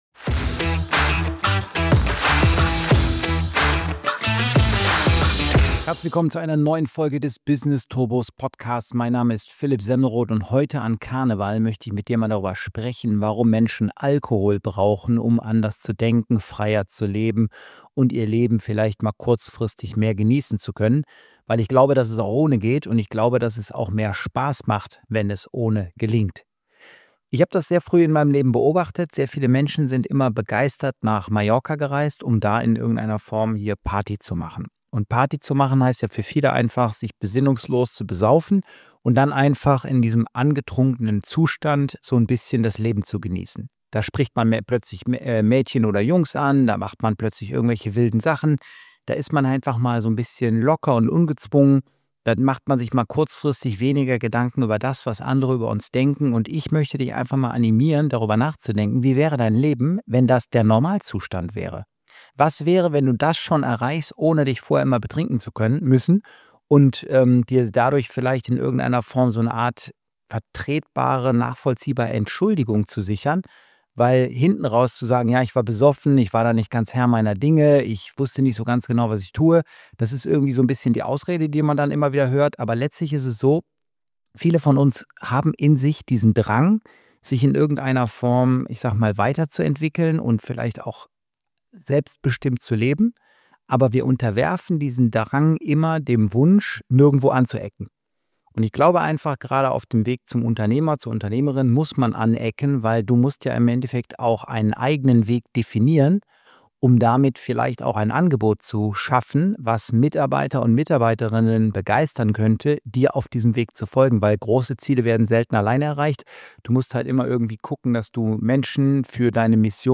An Karneval reden alle über Alkohol – ich rede darüber, warum du ihn nicht brauchst, um mutiger zu denken, freier zu leben und bessere Entscheidungen zu treffen. In dieser Solo-Folge zeige ich, wie „anders denken“ im Alltag ohne Promille funktioniert und...